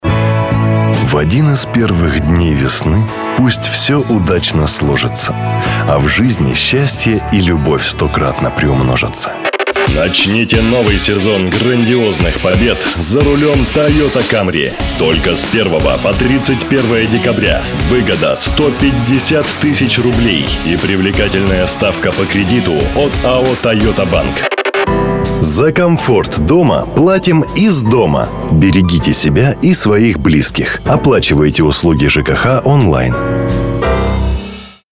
Статус: Диктор доступен для записи.
Тракт: Микрофоны: AKG SolidTube и AKG PERCEPTION 220 Предусилитель: SPL Channel One Звуковые карты: ESI Juli@, Roland Rubix 24 Акустически подготовленное помещение (дикторская кабина на основной студии, поролоновая обивка в домашней)